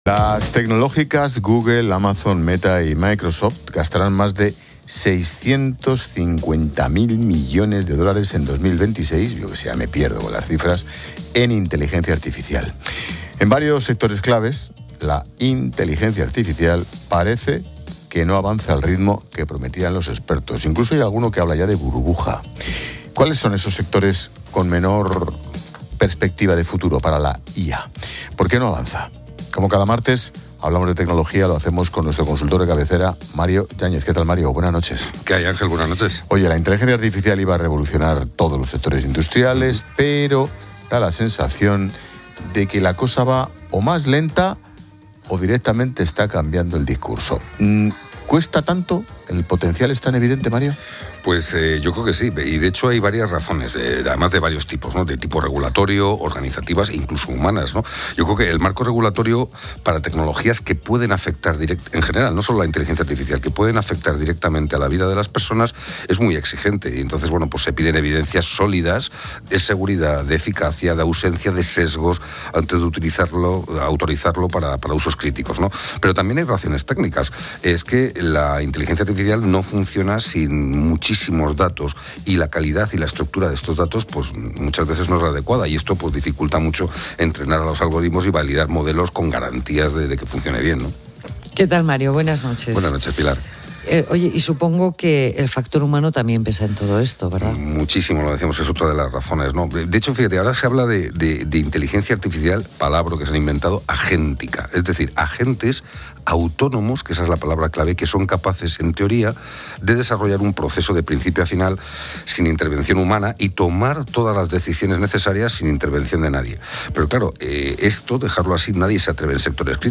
experto en IA